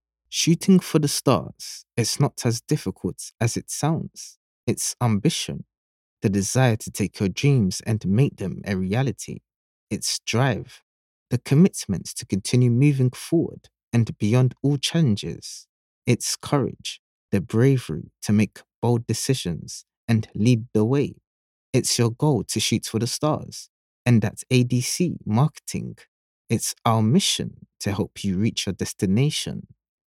I've a versatile voice.
All records with the profesional quality of my studio....
Spanish - Spain (Castilian) Teenager (13-17) | Adult (30-50)